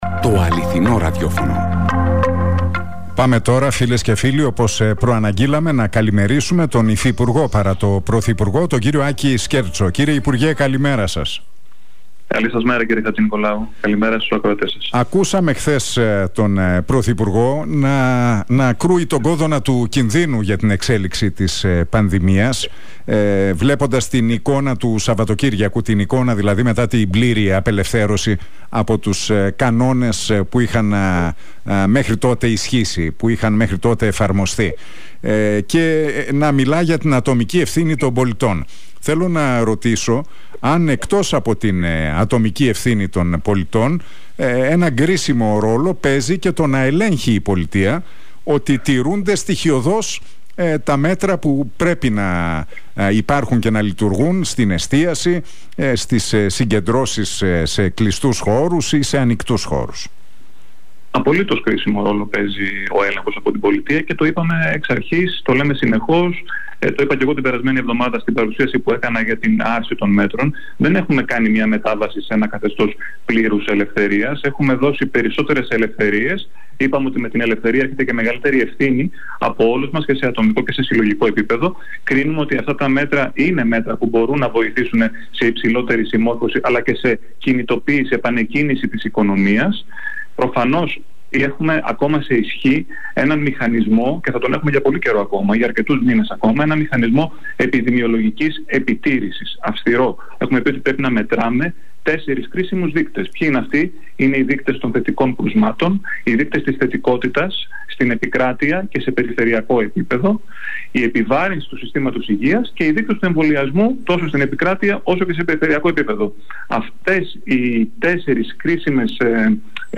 Ο υφυπουργός παρά τω Πρωθυπουργώ, αρμόδιος για τον συντονισμό του κυβερνητικού έργου, Άκης Σκέρτσος, μίλησε στον Realfm 97,8 και στην εκπομπή του Νίκου Χατζηνικολάου.